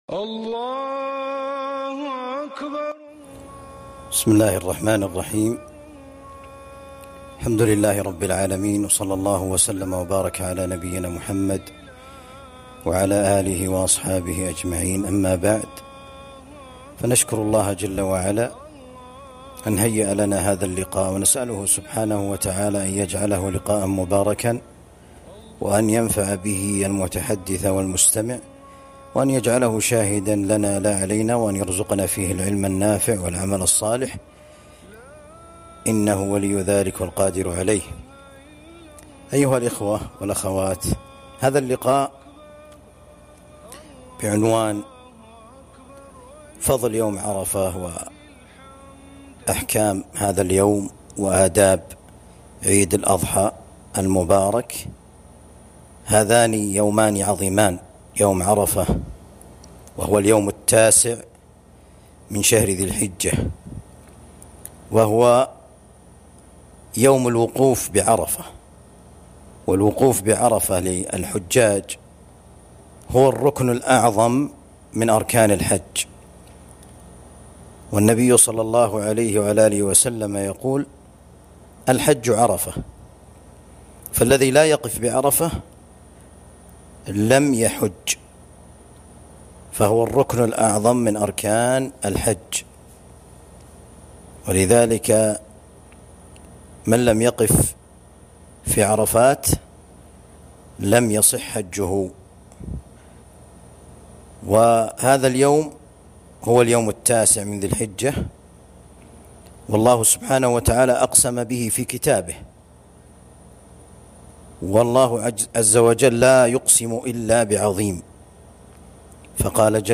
محاضرة بعنوان أحكام عرفة وآداب العيد